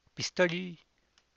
pistoly[pisto’lly]